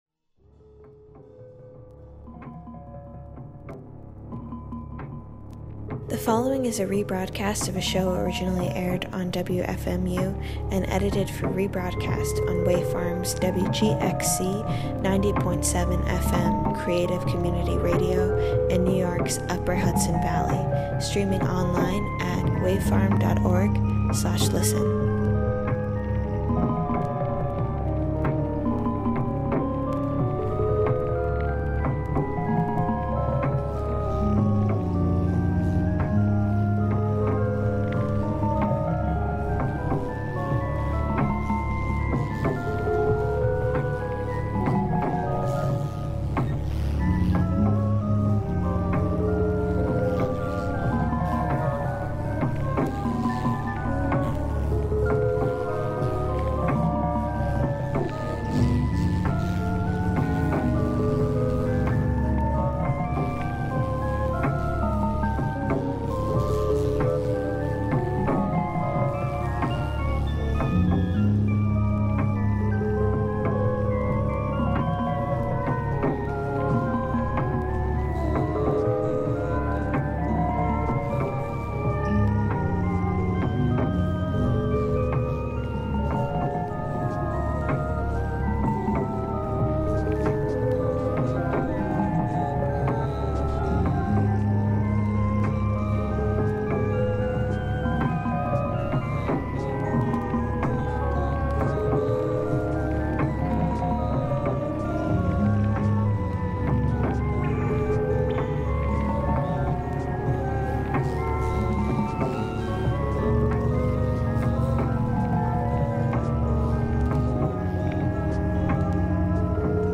discussions of the brain, brain waves, and the body over electronic swamp.
In a stew of intimate electronics, bad poetry, and tender murk, we swap tongues and reach for more.